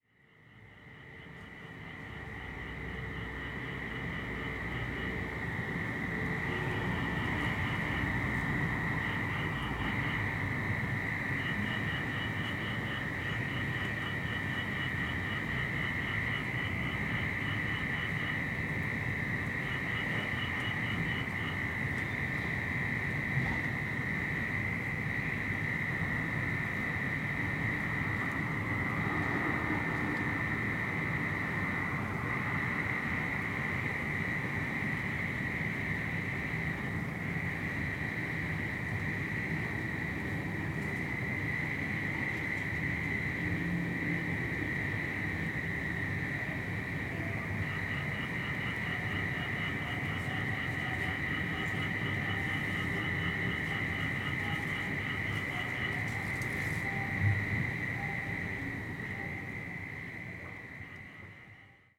ZOOM H6, Sennheiser MKH8020, 2019年9月28日 山形県鶴岡市
庭のマサキに潜むクサヒバリの鳴き声です。小泉八雲 ラフカディオ・ハーンは「草雲雀」のなかで「かすかな、かすかな銀鈴が波だちふるえるような声」と表現しました。
時間は午前11時頃。色んな声が聞こえますがセンター付近で長く連続的に鳴いているのがクサヒバリです。時々リー、リーと入ってくるのはアオマツムシ。ビビビビと鋭く鳴くオカメコオロギはタンボオカメコオロギかな？